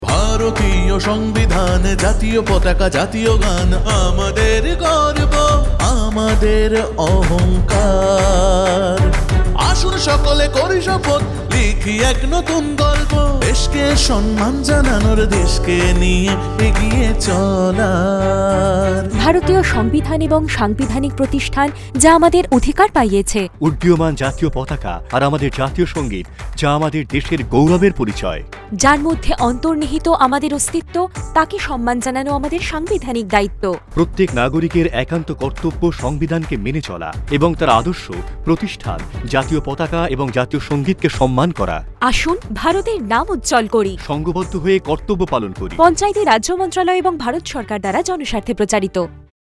36 Fundamental Duty 1st Fundamental Duty Abide by the Constitution and respect National Flag and National Anthem Radio Jingle Bangla